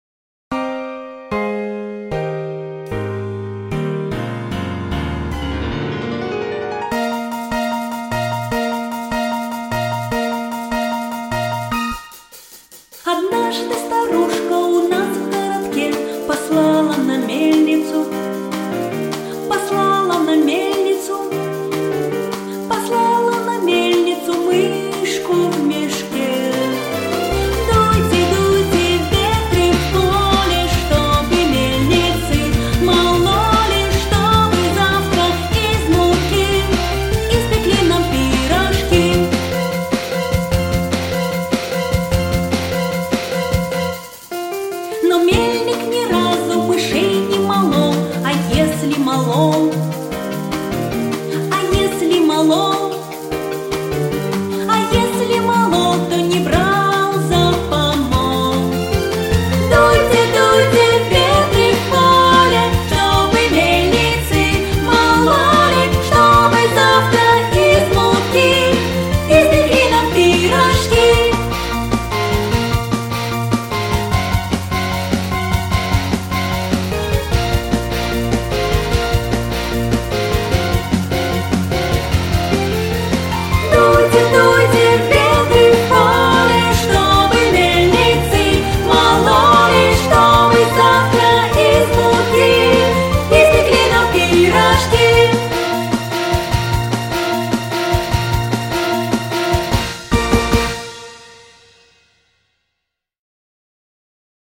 Звуки ветра
На этой странице собраны звуки ветра: от нежного шелеста листвы до мощных порывов в горах.